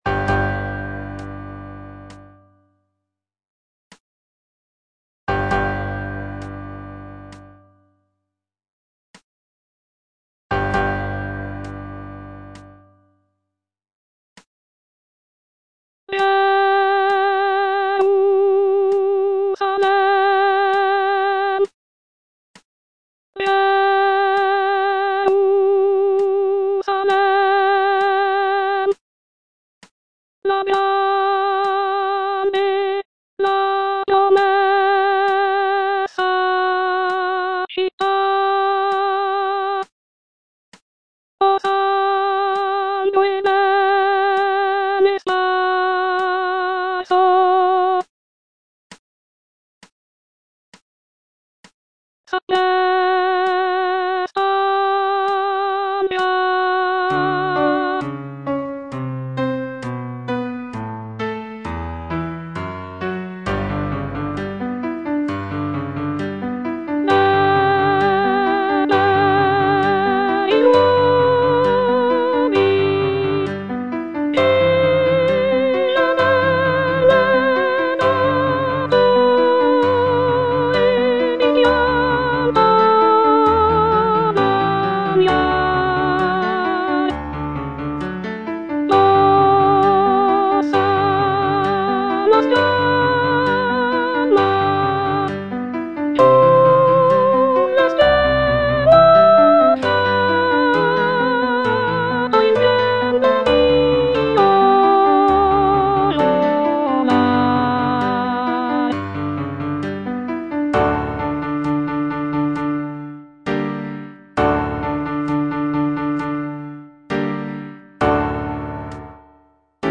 The powerful and emotional choral writing, along with the grand orchestration, creates a sense of reverence and anticipation for the epic quest ahead.